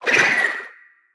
Sfx_creature_babypenguin_death_swim_04.ogg